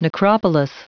Prononciation du mot necropolis en anglais (fichier audio)
Prononciation du mot : necropolis